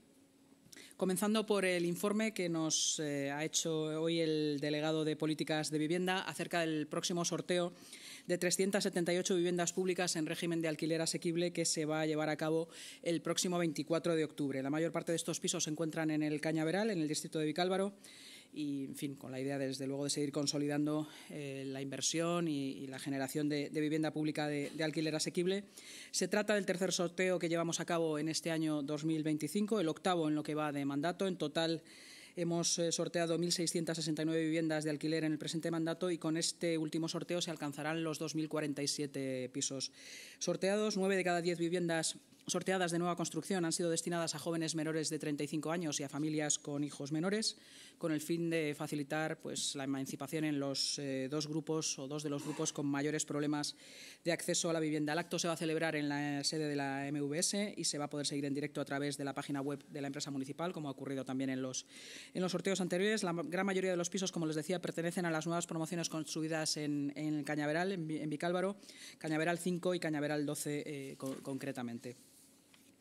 Así lo ha anunciado la vicealcaldesa y portavoz municipal, Inma Sanz, en la rueda de prensa posterior a la Junta de Gobierno celebrada hoy, a la que el delegado de Políticas de Vivienda y presidente de la EMVS Madrid, Álvaro González, ha informado de este nuevo sorteo.
AUDIO-SANZ.-Anuncio-sorteo-de-viviendas.mp3